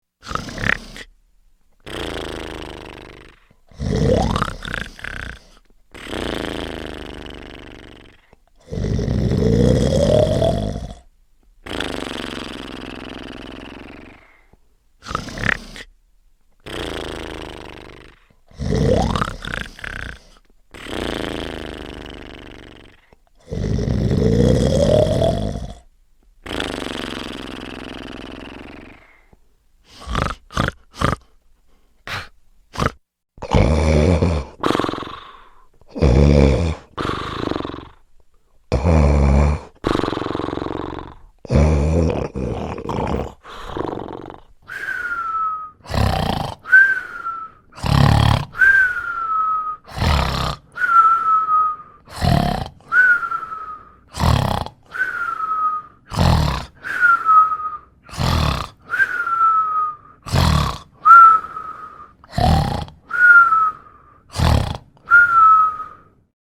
Cartoon Heavy Snoring